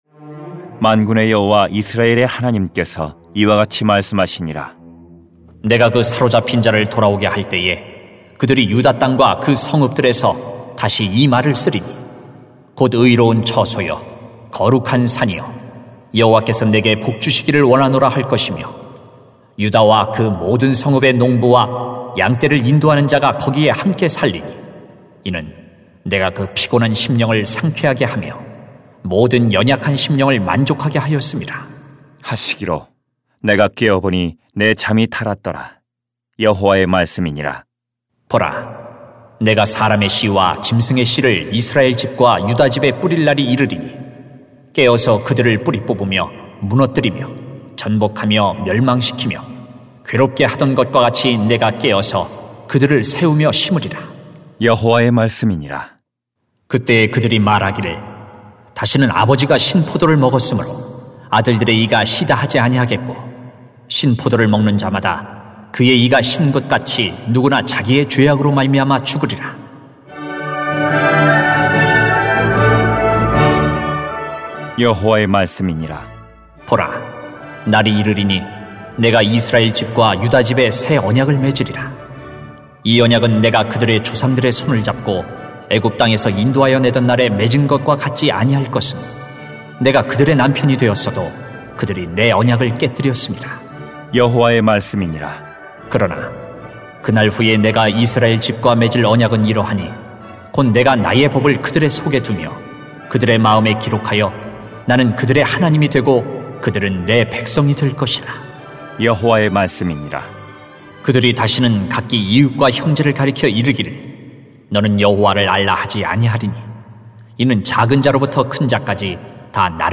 [렘 31:23-40] 은혜의 능력이 더 큽니다 > 새벽기도회 | 전주제자교회